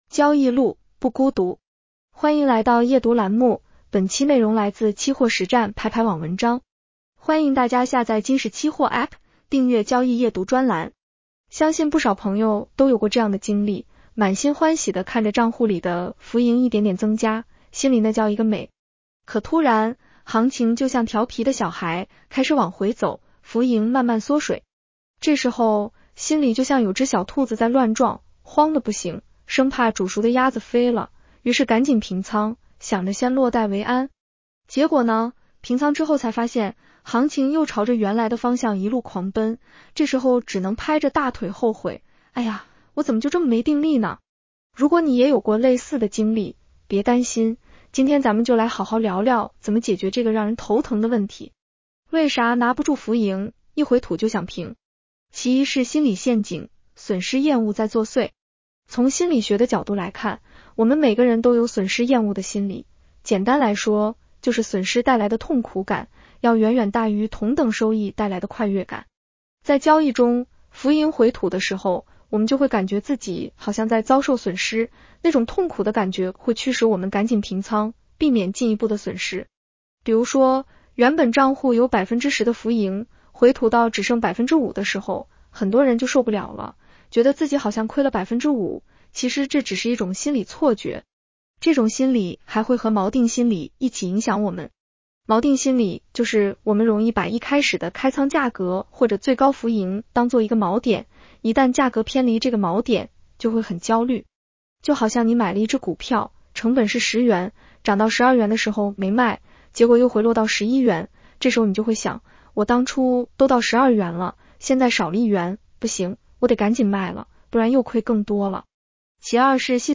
女声普通话版 下载mp3 如何解决交易中拿不住浮盈，一回吐就想平的问题？